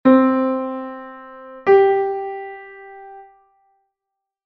do_sol.mp3